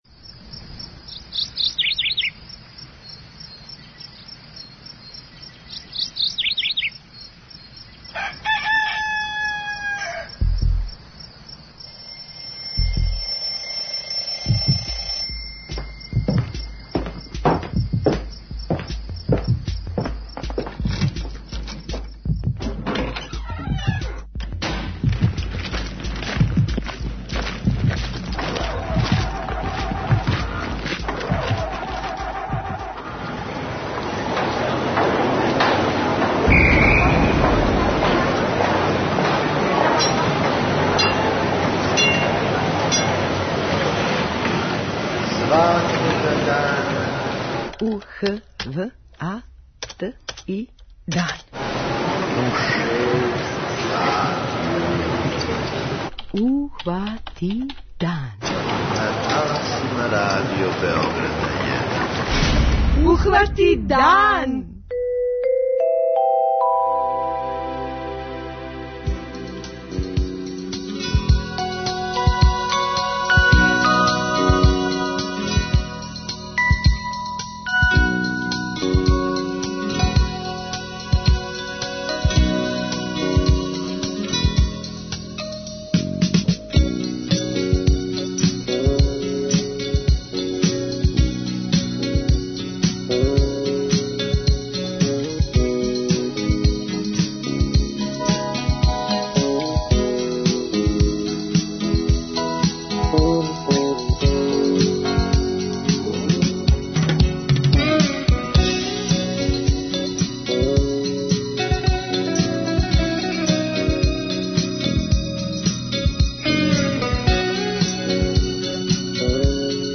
преузми : 29.32 MB Ухвати дан Autor: Група аутора Јутарњи програм Радио Београда 1!